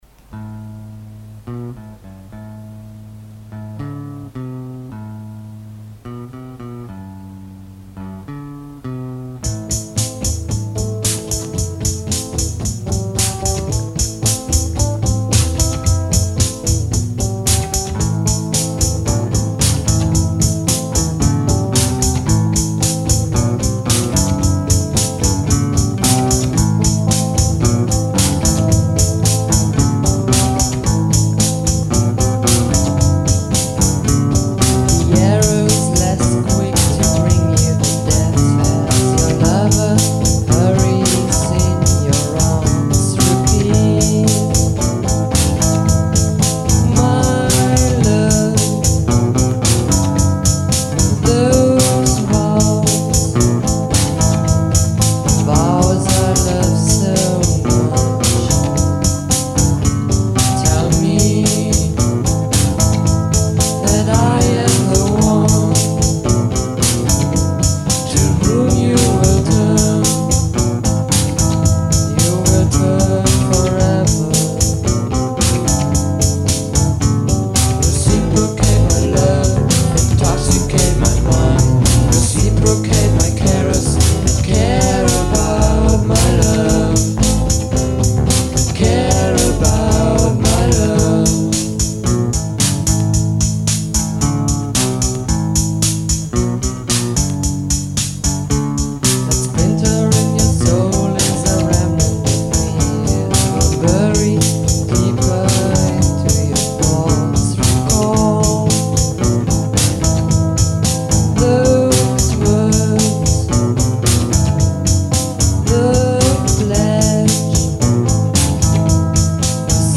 Ein ausgeliehenes 4 Spur Kassetten- Deck, ein Echolette BS 40 Bassverstärker mit einer alten Hifi-Box und irgendeine Drummachine.
Ich spiele alles und mache die Backing Vox.